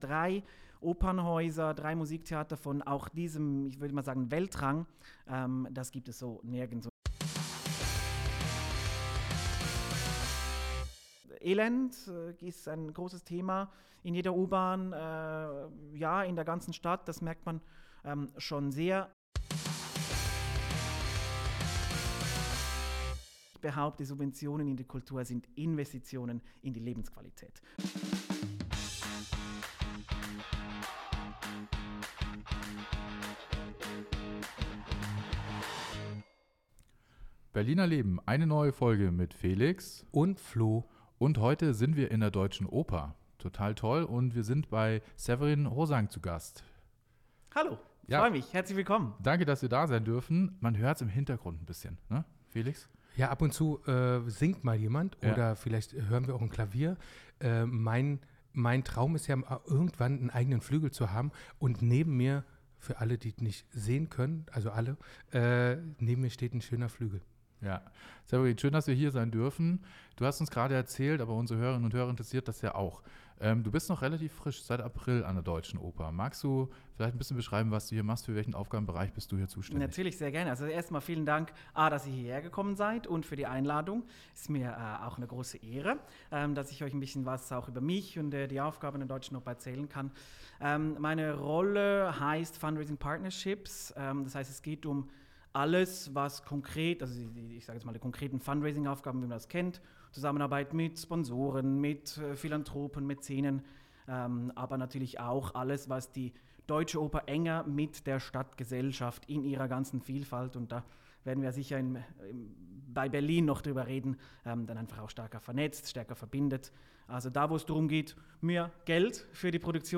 Ein positives, kluges und total ansteckend optimistisches Gespräch aus den heiligen Räumen der Deutschen Oper.